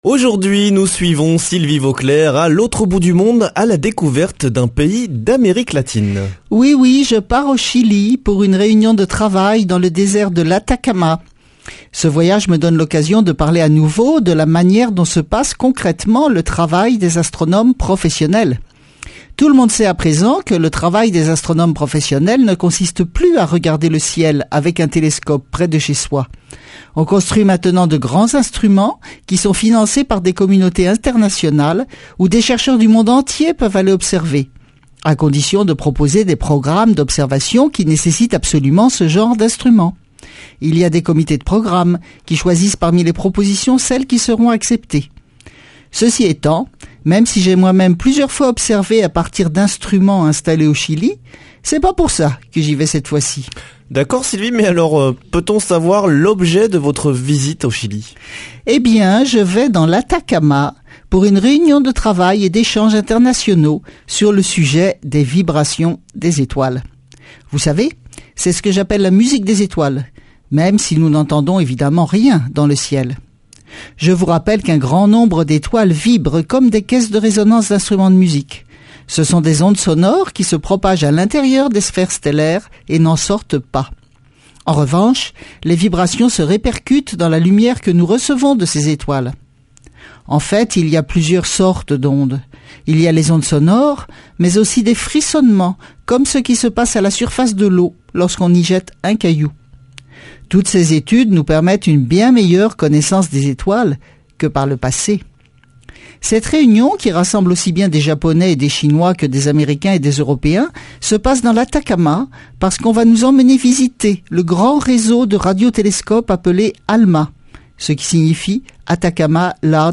Speech
Astrophysicienne